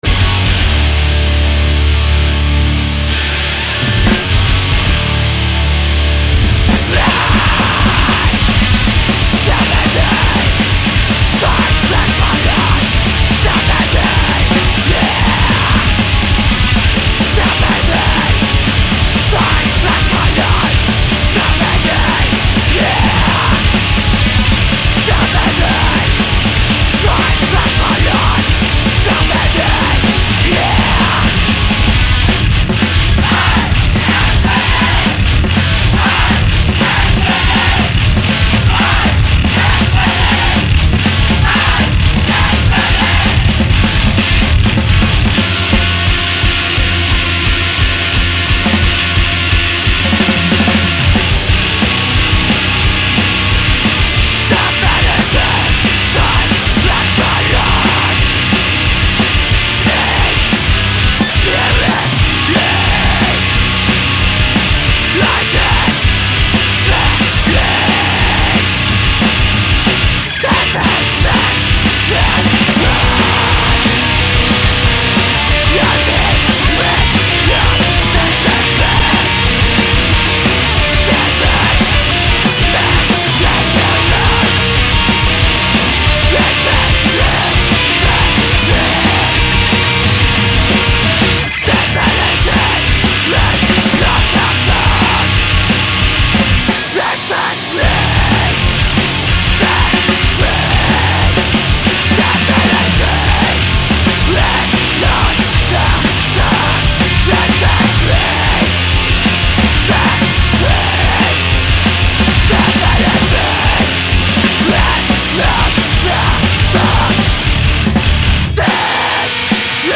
Hier 2 "unmastered" Tracks der LP: